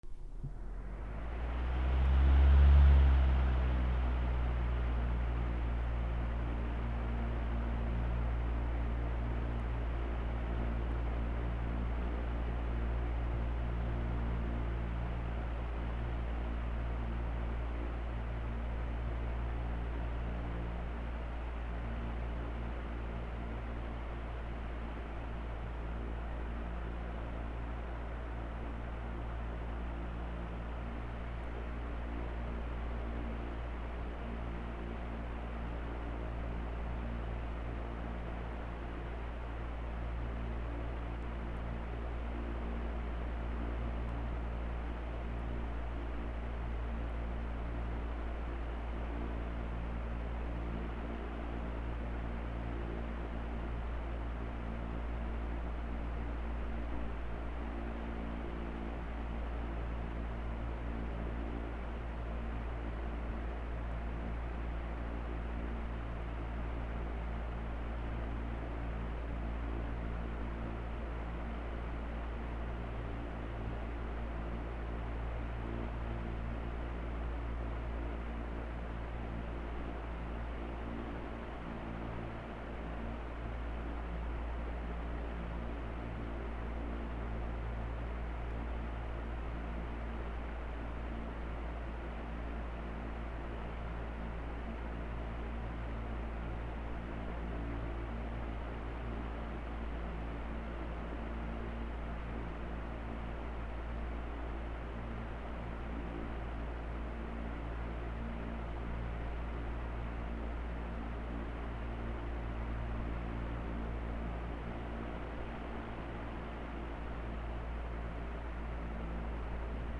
扇風機(弱)
/ M｜他分類 / L10 ｜電化製品・機械